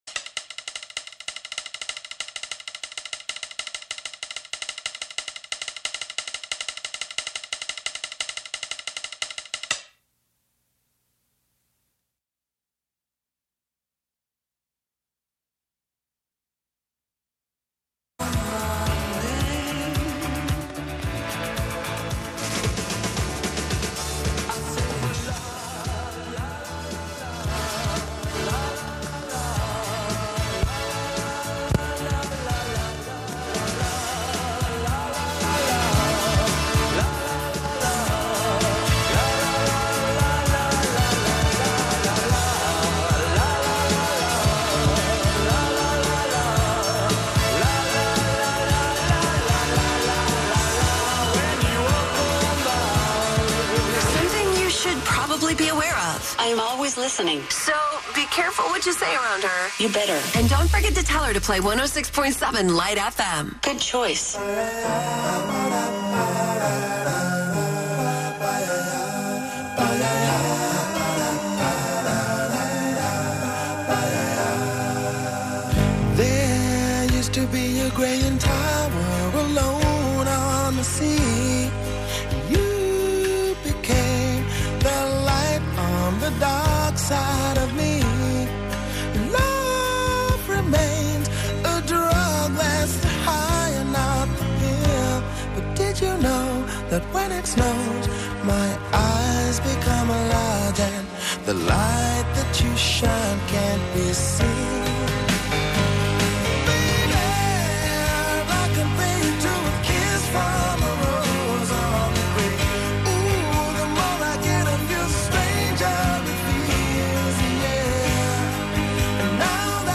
11am Live from Brooklyn, New York